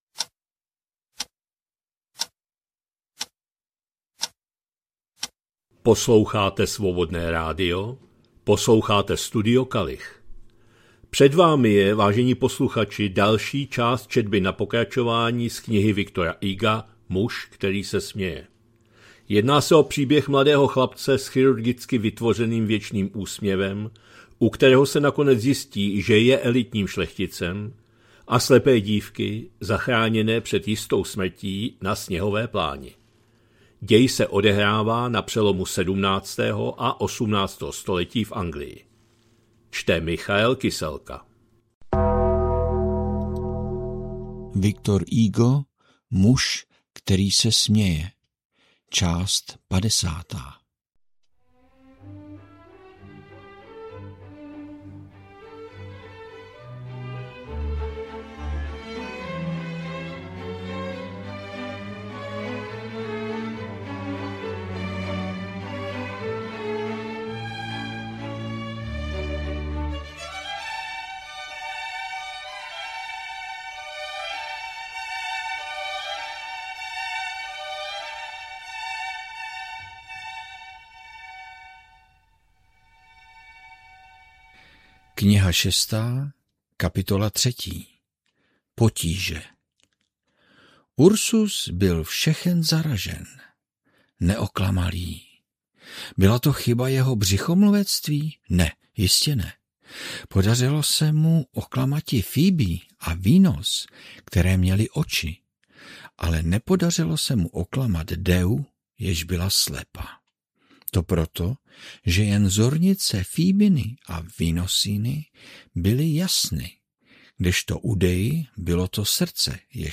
2026-02-27 – Studio Kalich – Muž který se směje, V. Hugo, část 50., četba na pokračování